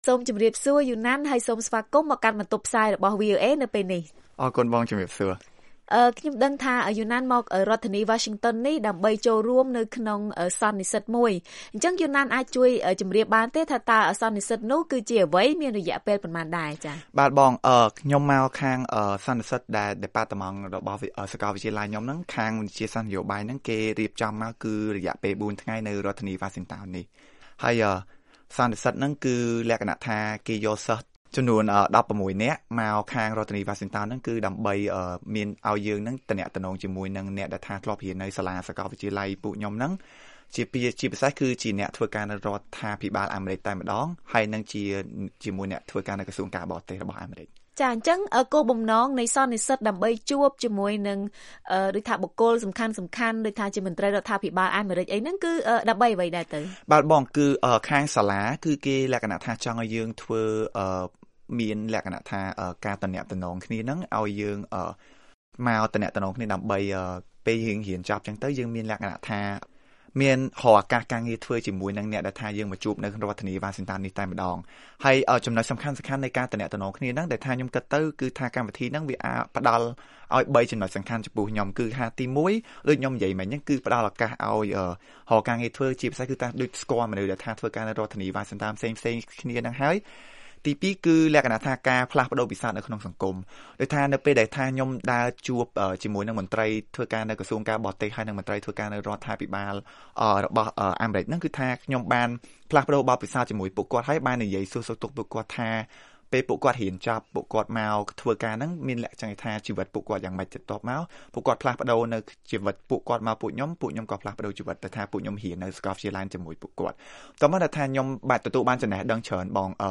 បទសម្ភាសន៍៖ អត្ថប្រយោជន៍នៃការបង្កើតទំនាក់ទំនងសម្រាប់និស្សិតខ្មែរនៅបរទេស